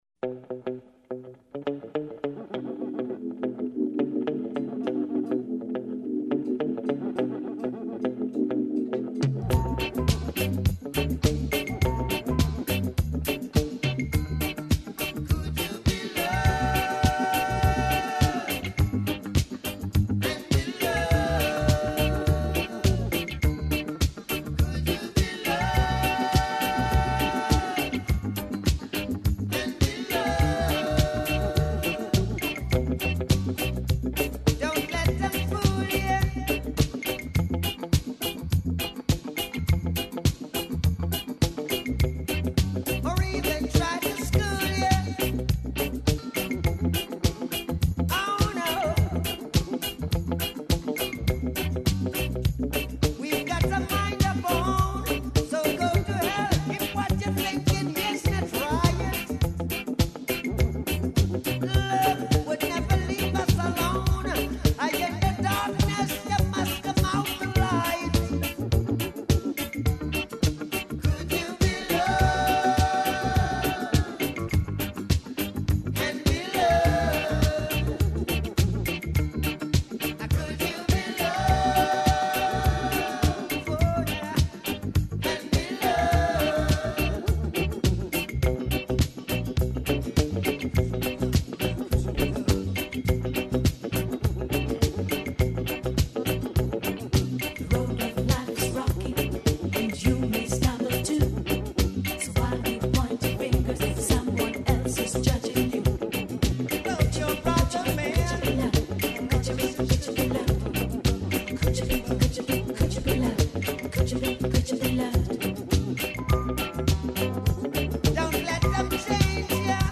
Викенд је идеално време за чињење свега онога што не стижете у току радне недеље. Како за сада само на сликама можете да видите неке од београдских лагума, звуком и причом дочараћемо вам изглед и атмосфeру једног лагума у Земуну.